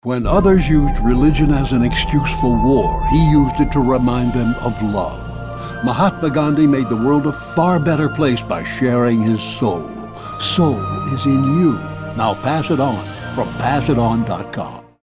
Mahatma Gandhi went without food to help many people forget their hate. He made the world a better place through peace. Hear the Radio Commercial and Pass it On.